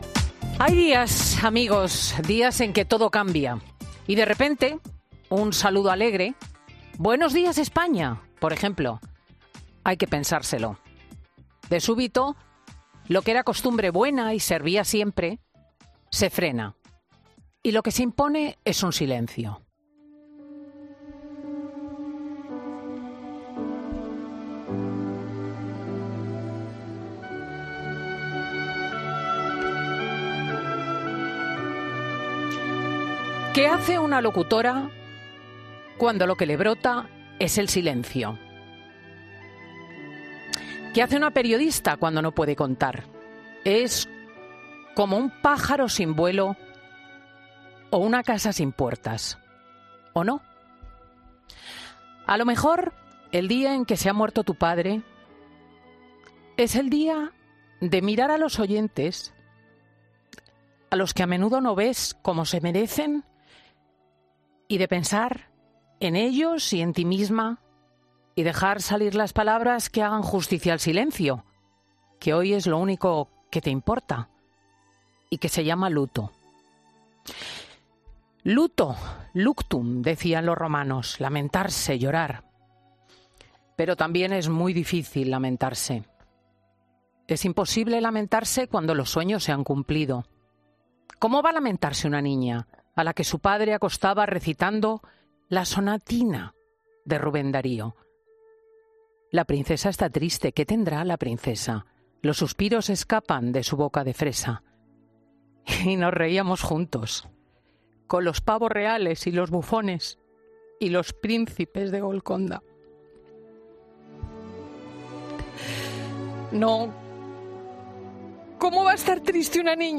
Monólogo de Cristina López Schlichting - COPE